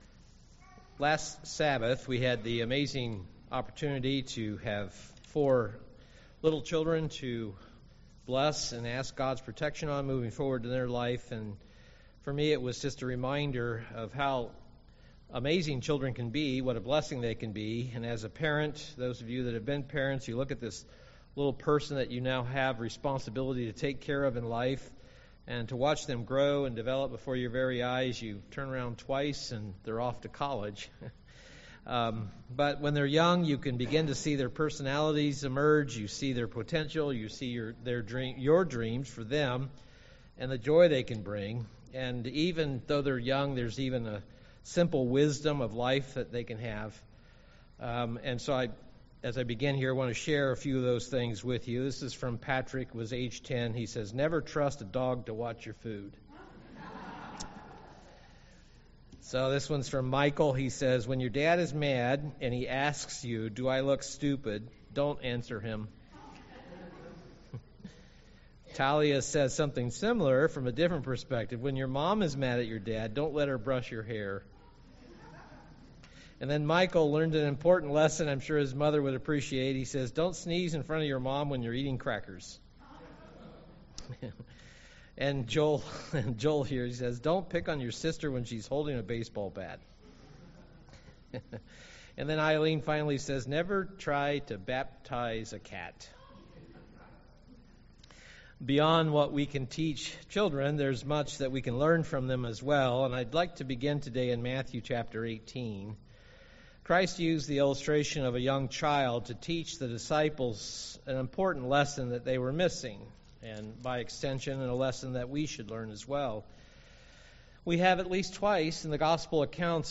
Given in Milwaukee, WI
UCG Sermon become childlike become like a child become like little children innocence Faith trust Trust in God Studying the bible?